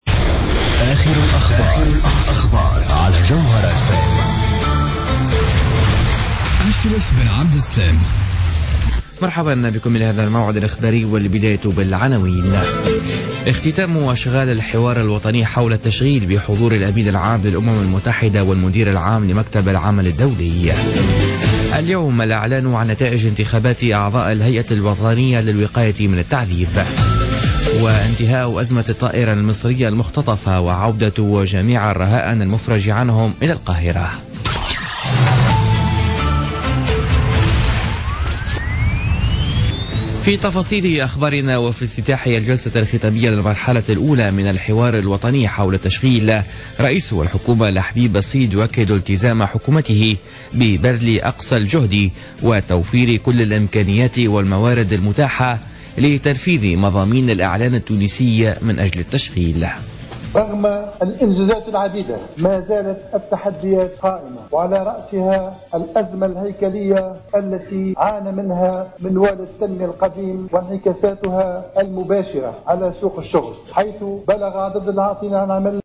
نشرة أخبار منتصف الليل ليوم الاربعاء 30 مارس 2016